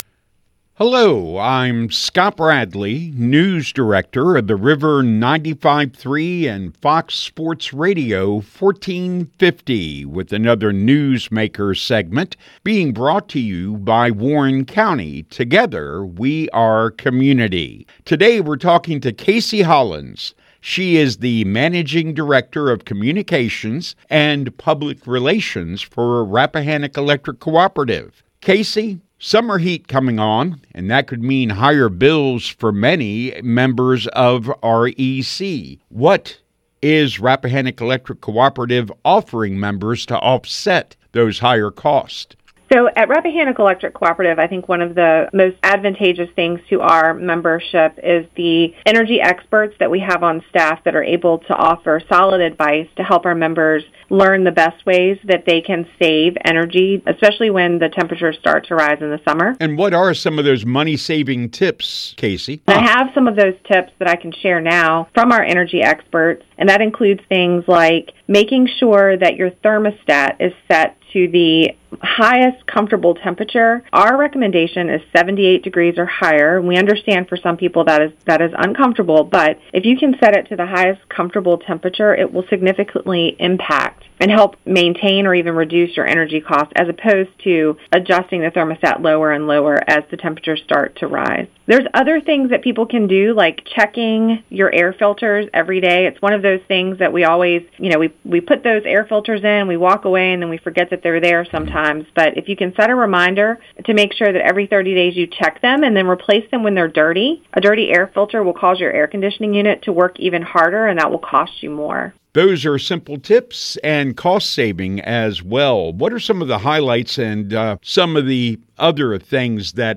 talks with 95.3 The River and gives tips on how to save money on your electric bill.